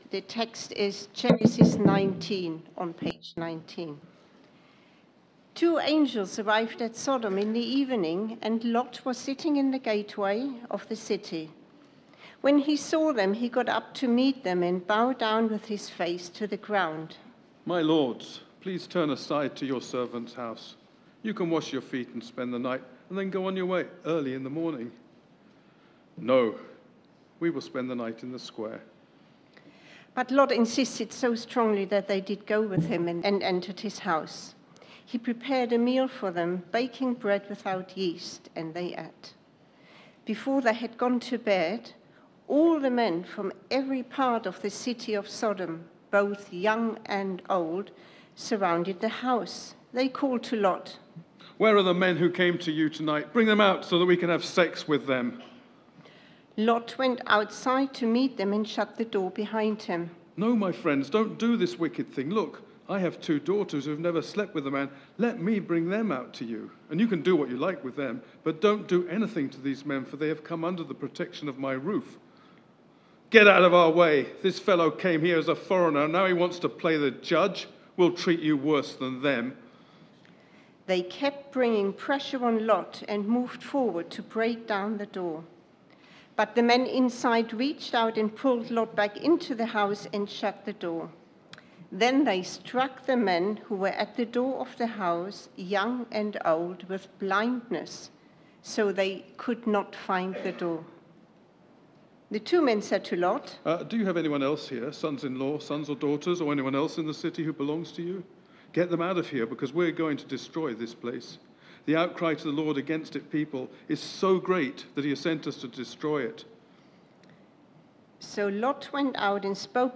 Media for Sunday Service on Sun 10th Mar 2024 10:00
Theme: Sermon